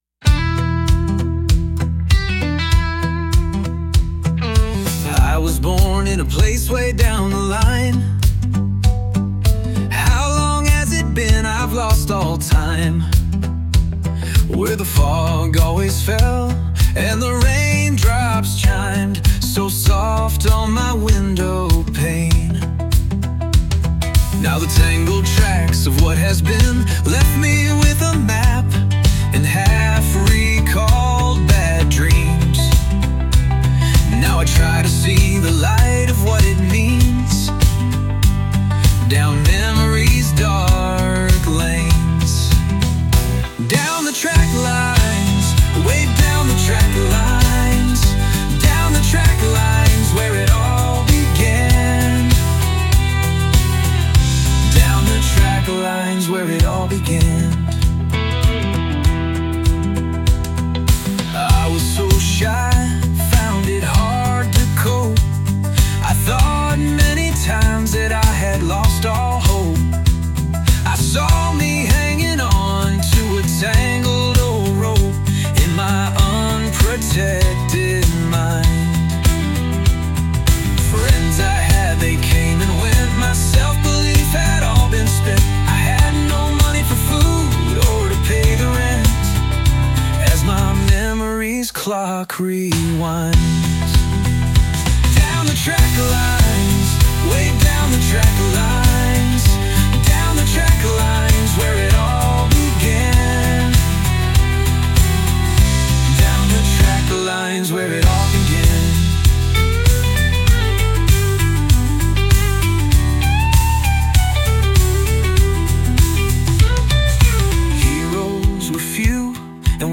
deeply reflective country ballad
poignant, introspective song